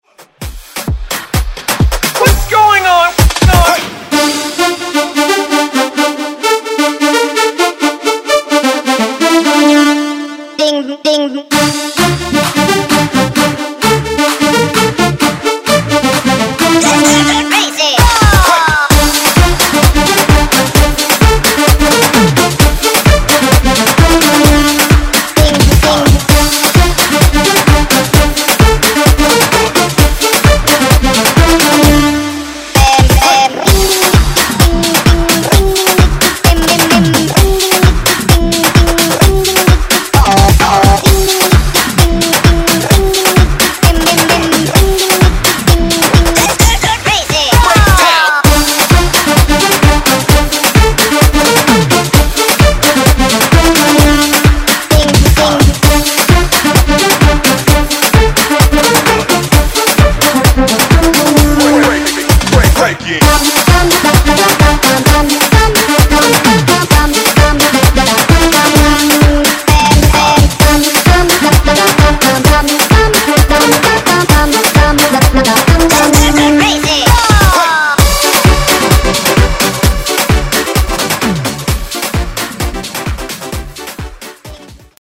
Genres: DANCE , RE-DRUM , TOP40 Version: Clean BPM: 132 Time